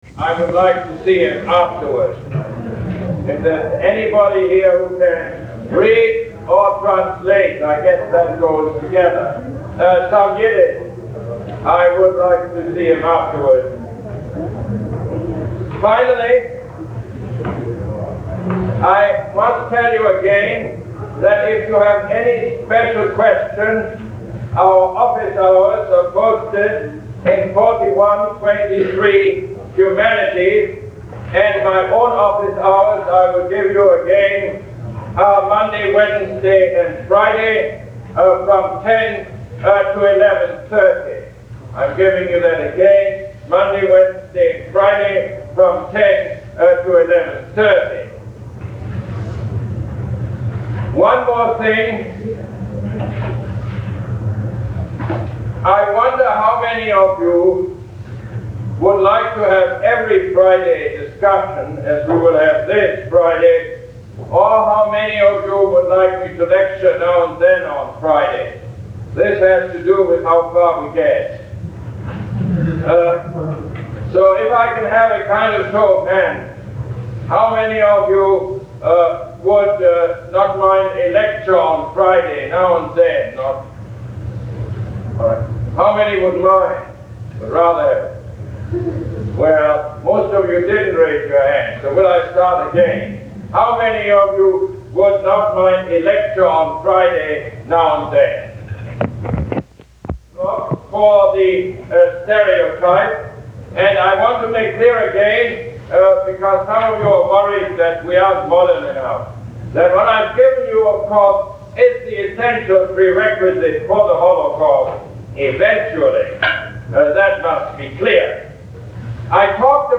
Lecture #11 - March 10, 1971